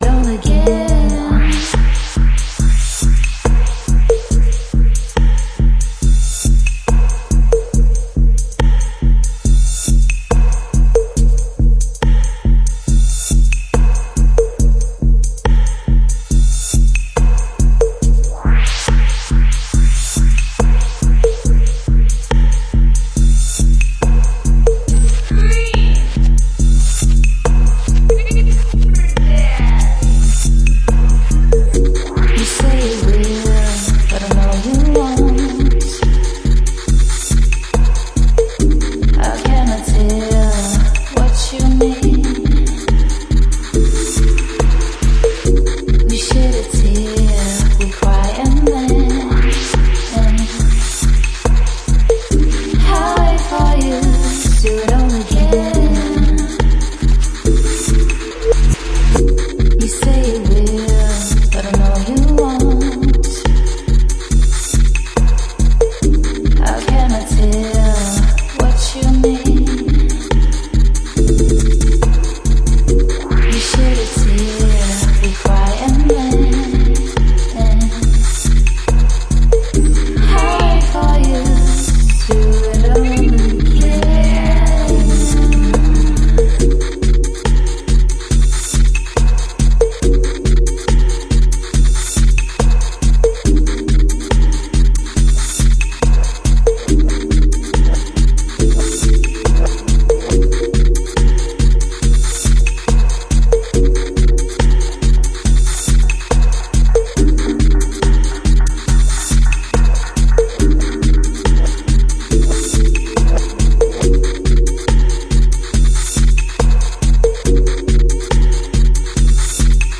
Sweet vocals
poppy a-side
a hypnotic 4/4-heartbeat
Electronix House Bass